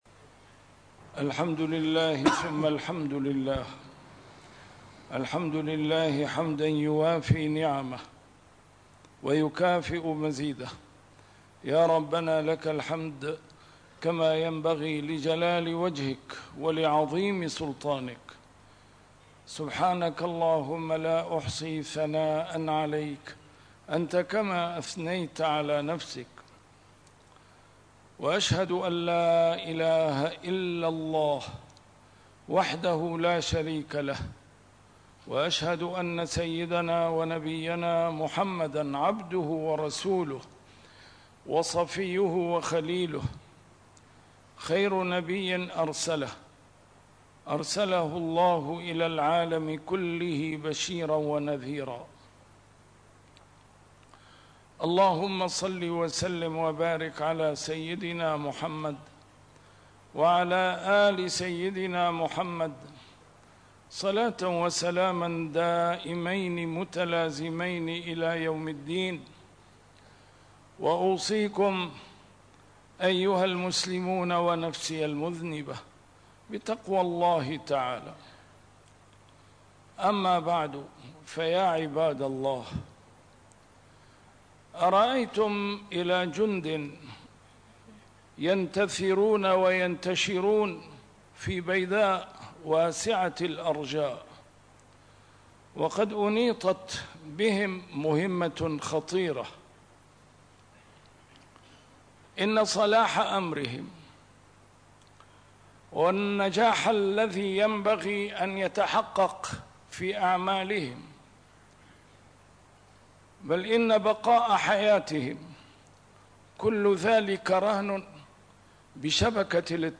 A MARTYR SCHOLAR: IMAM MUHAMMAD SAEED RAMADAN AL-BOUTI - الخطب - عندما تستهين الأمة بالصلاة